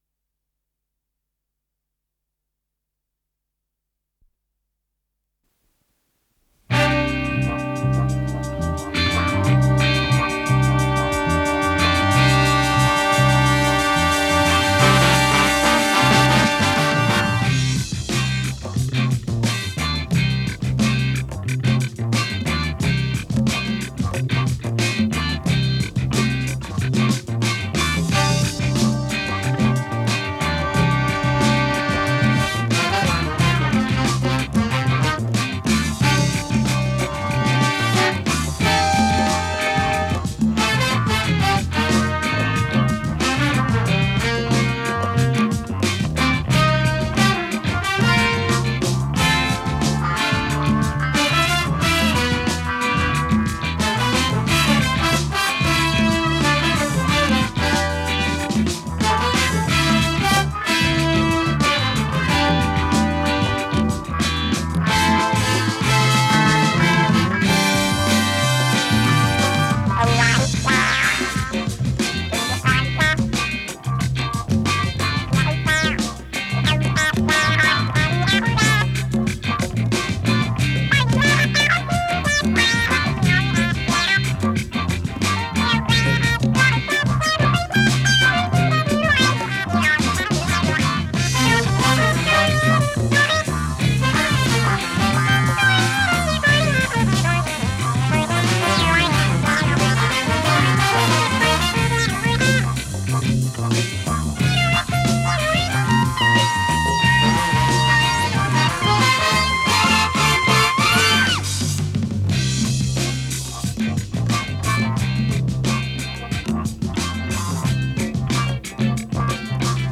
с профессиональной магнитной ленты
Скорость ленты38 см/с
Тип лентыORWO Typ 106